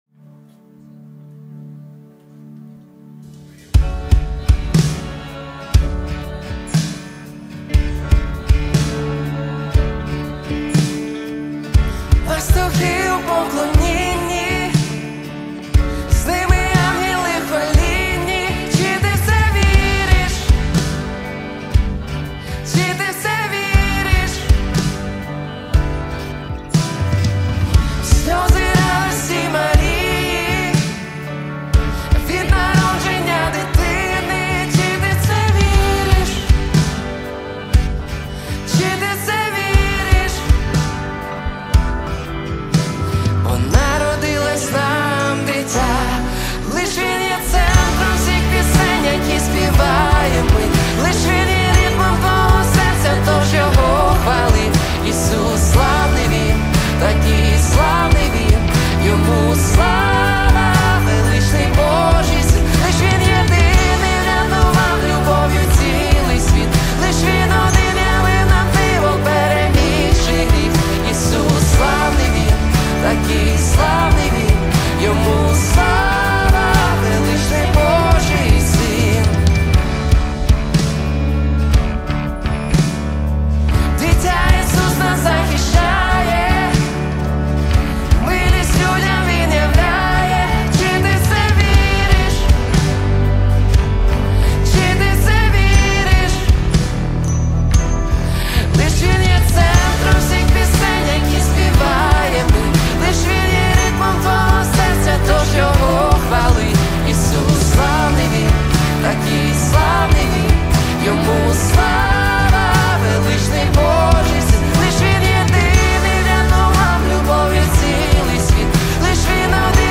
205 просмотров 31 прослушиваний 5 скачиваний BPM: 120